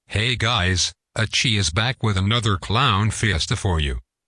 Back with another clown fiesta (tts)